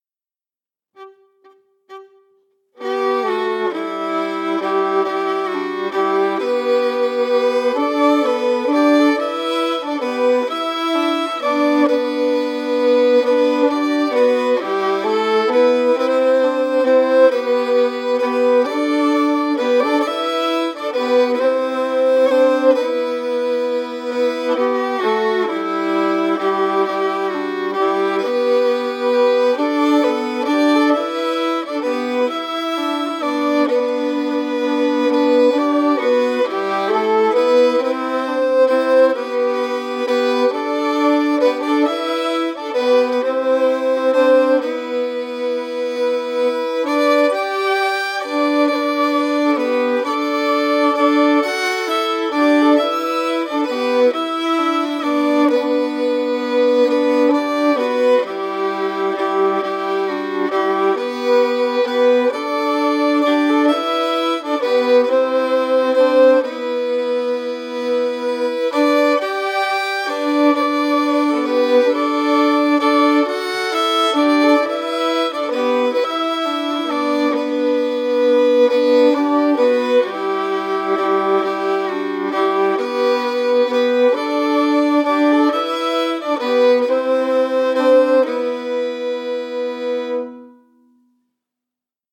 Key: Am or Em*
Form: Waltz
Harmony emphasis
Region: Ireland
*Notes: This tune is commonly played in Am but the MP3 audio clips and sheet music above are in Em.